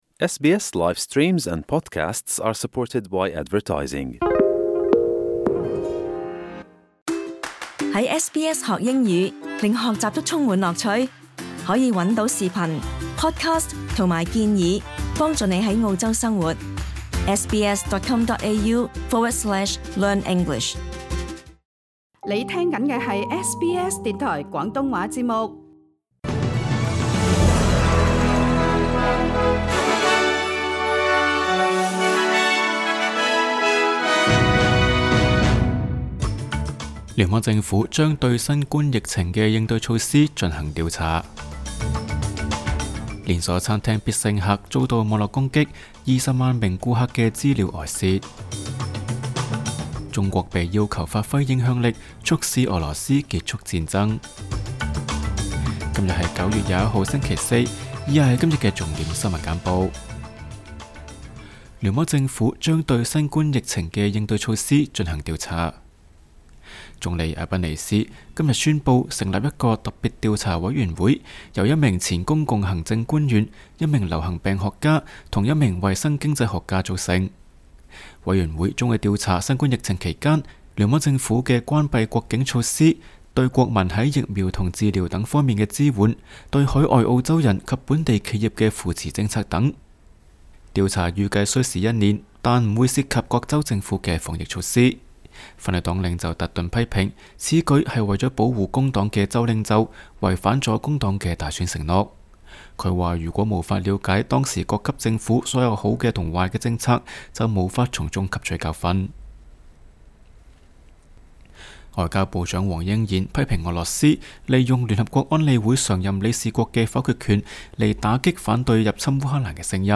SBS 廣東話節目新聞簡報